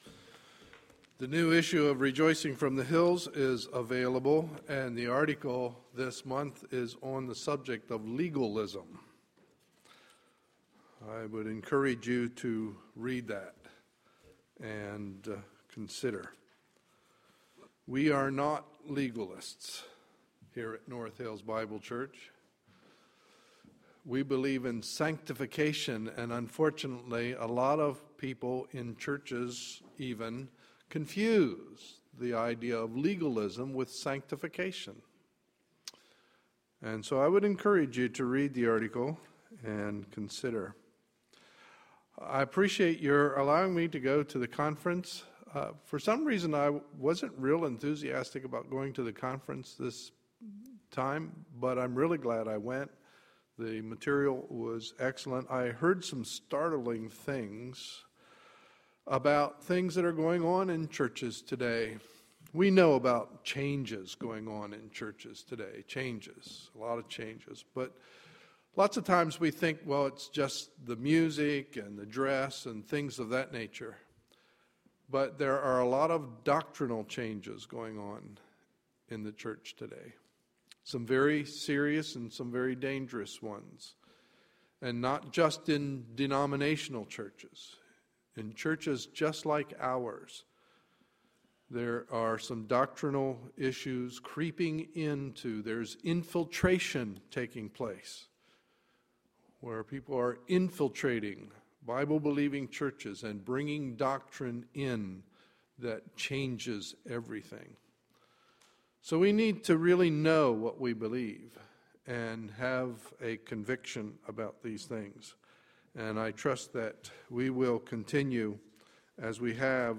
Sunday, April 28, 2013 – Morning Service